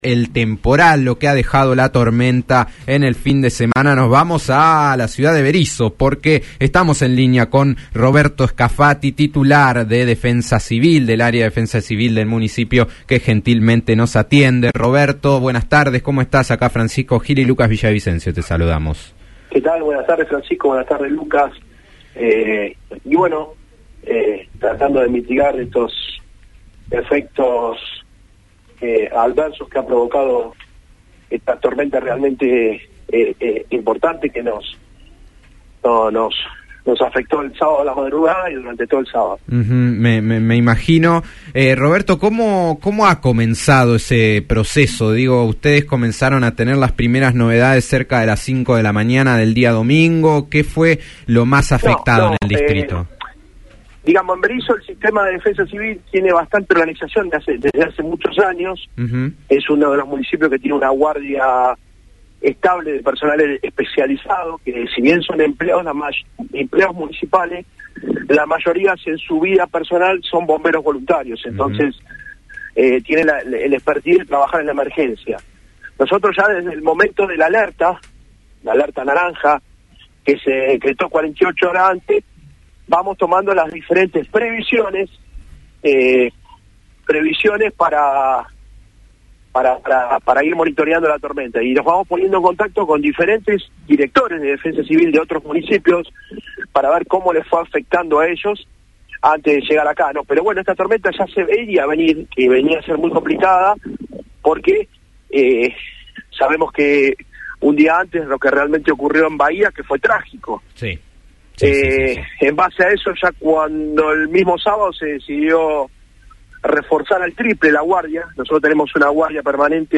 Tras el temporal que se desató en la noche del sábado y la madrugada del domingo en la Región Capital de la provincia de Buenos Aires, el director de Defensa Civil de Berisso, Roberto Scafati, destacó en diálogo con Radio La Plata 90.9 FM, los trabajos que se realizaron desde su área para socorrer a todos los barrios de la ciudad. Además, el funcionario alertó sobre la inusual crecida del Río de La Plata que se espera para la madrugada de este martes y dio una serie de recomendaciones para que la población sepa como reaccionar ante estos fenómenos meteorológicos.